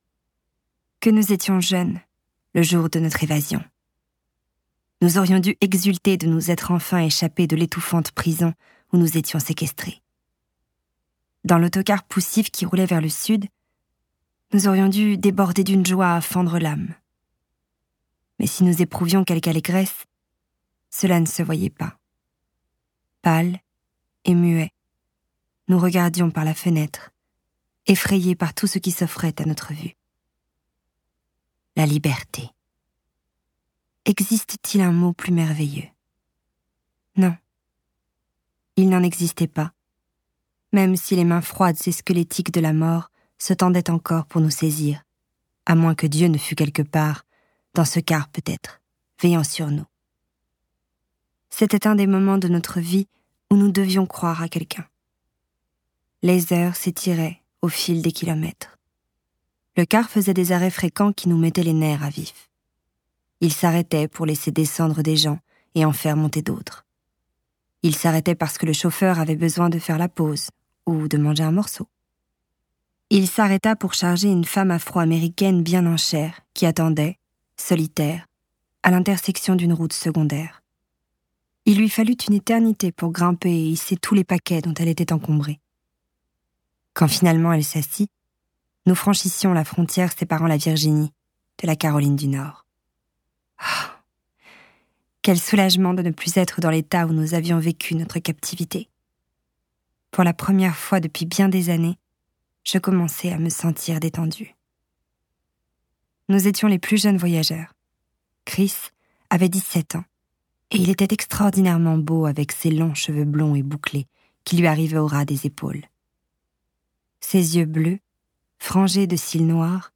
je découvre un extrait - Pétales au vent - Fleurs captives - Tome 2 de V.C Andrews